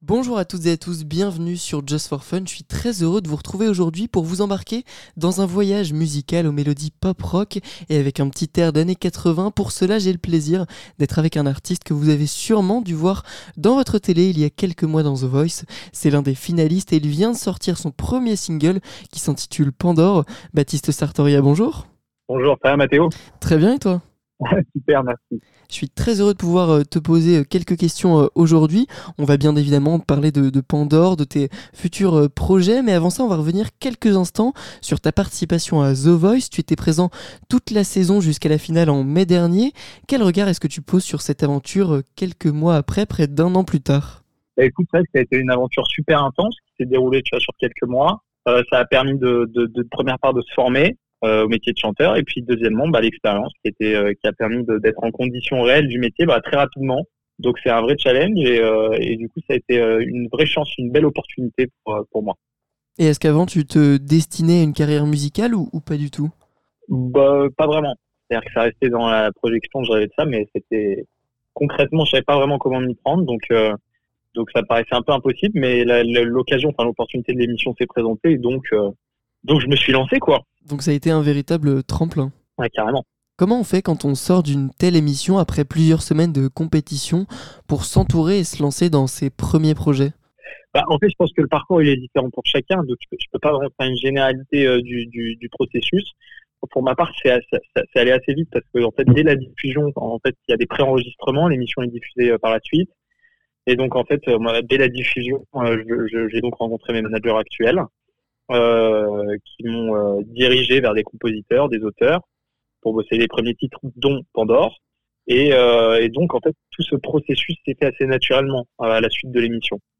Découvrez son histoire et ses aspirations musicales à travers cette interview !